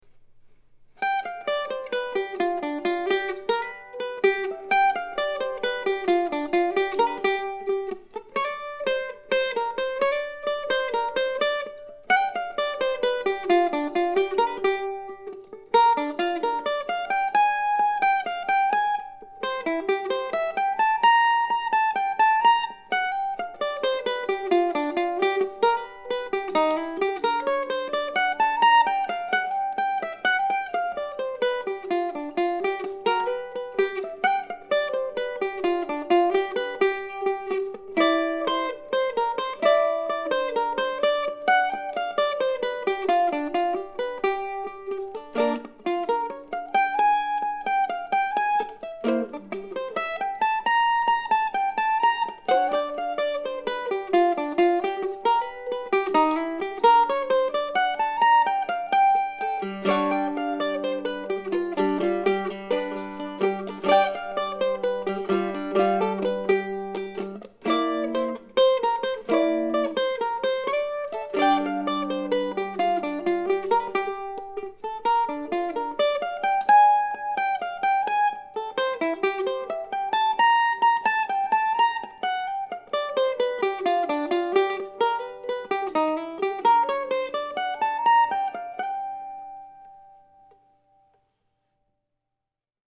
So tonight, for the last tune of 2019, I'm sharing a solo mandolin version of the Bear Creek Blues.